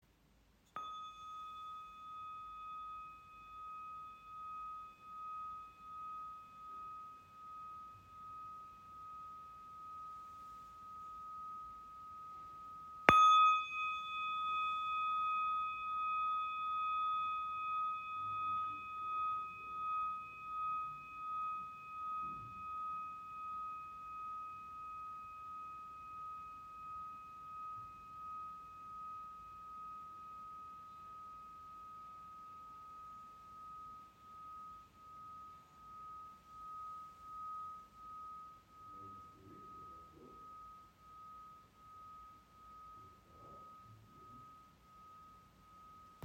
Klangglocke Terra | Grösse 6 cm | Tiefe Resonanz für Meditation und Klangarbeit
Handgeschmiedete Glocke mit warmen, erdenden Tönen und langer, klarer Resonanz.
Ihr Klang ist klar, lichtvoll und rein.
Der Klang dieser Glocken besitzt eine aussergewöhnlich lange Resonanz, vergleichbar mit der Serie Cosmos.